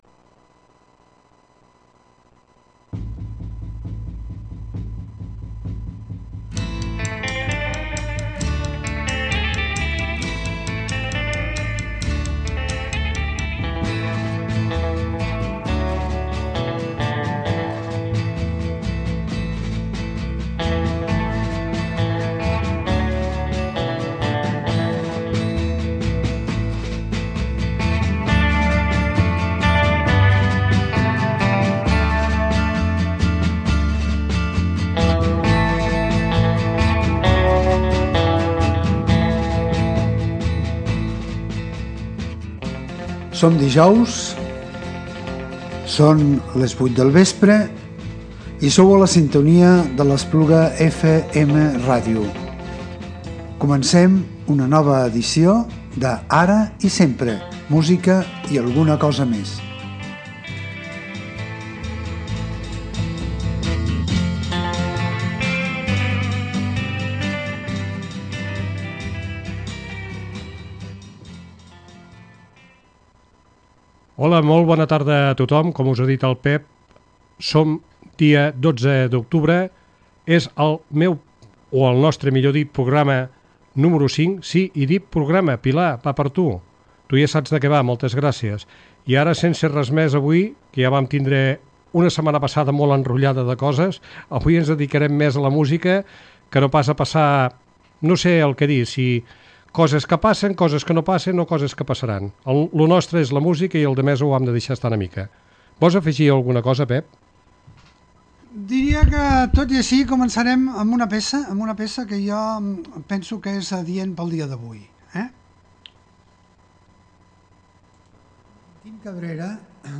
Un dijous més de musica variada de tots els tipus i tendències sense cap altra pretensió que la de fer passar una estona entretinguda.